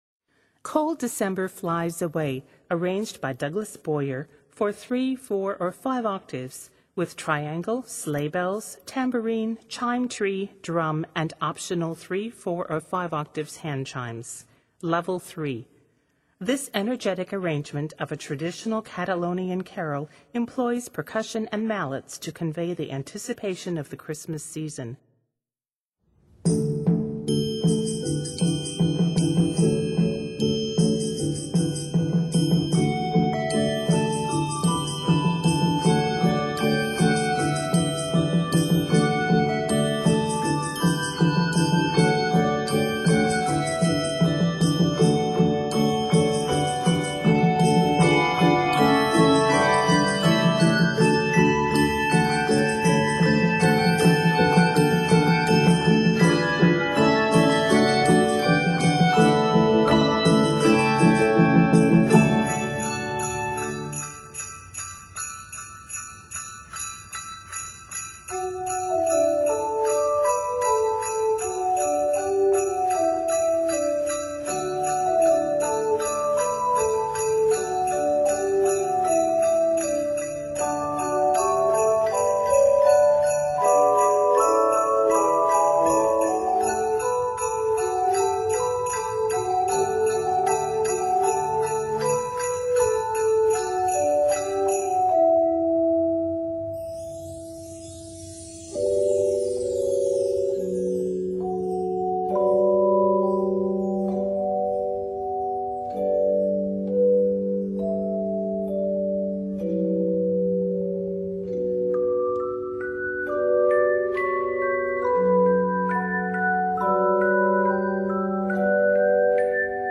This energetic arrangement of a traditional Catalonian carol